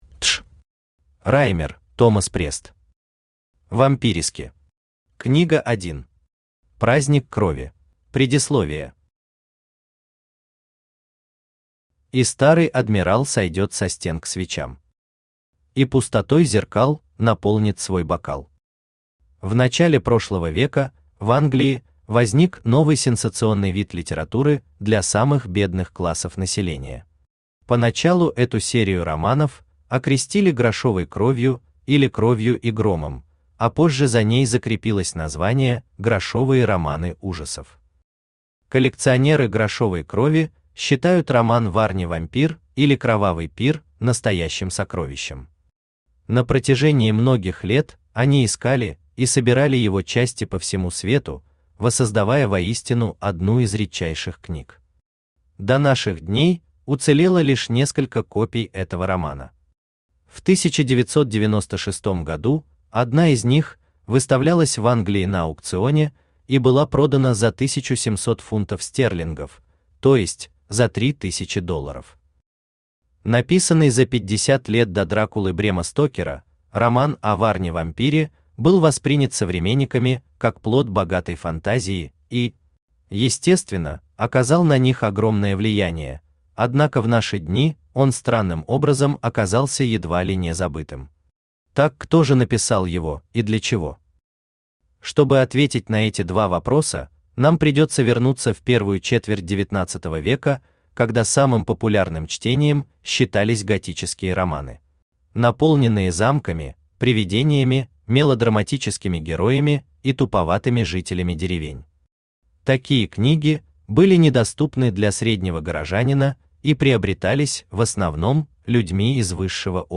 Аудиокнига Вампирески. Книга 1. Праздник крови | Библиотека аудиокниг
Раймер, Томас Прест Читает аудиокнигу Авточтец ЛитРес.